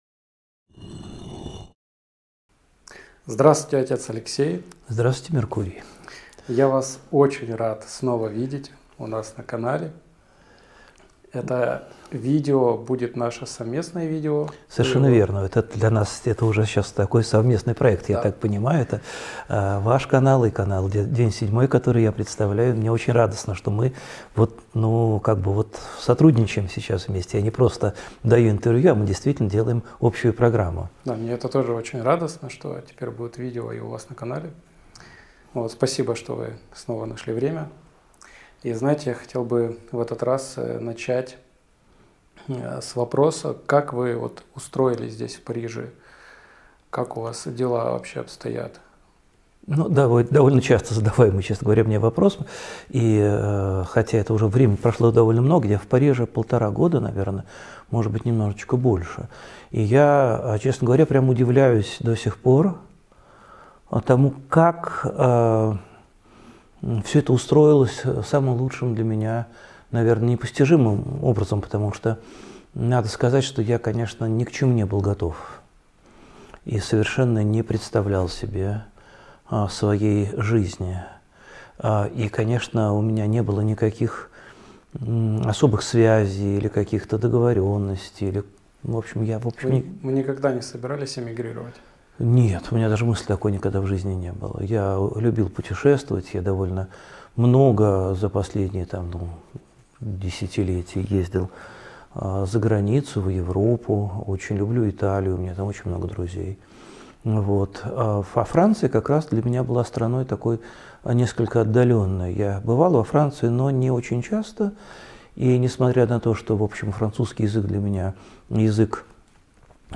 Эфир ведёт Алексей Уминский